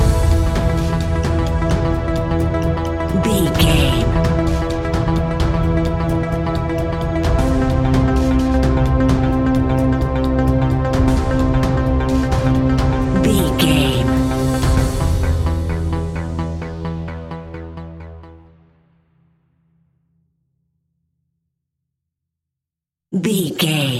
Aeolian/Minor
ominous
dark
eerie
synthesiser
electronic music
electronic instrumentals